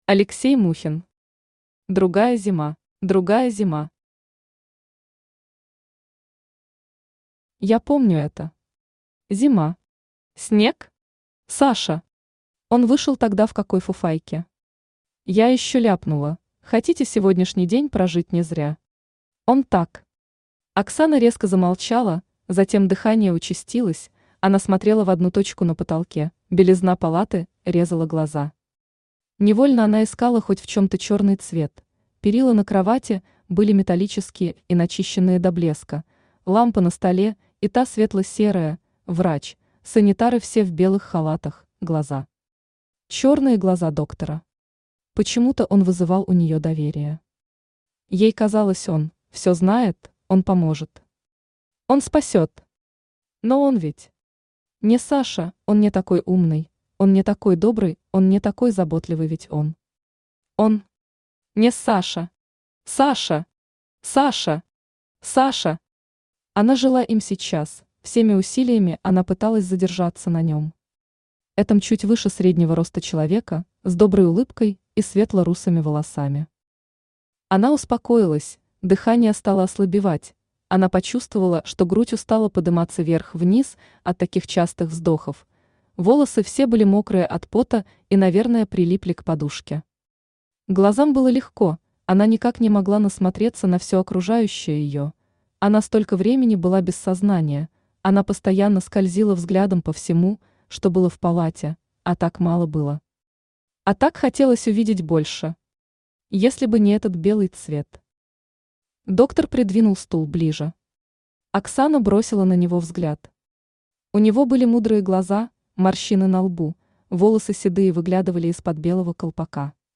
Аудиокнига Другая зима. Сборник рассказов | Библиотека аудиокниг
Сборник рассказов Автор Алексей Аркадьевич Мухин Читает аудиокнигу Авточтец ЛитРес.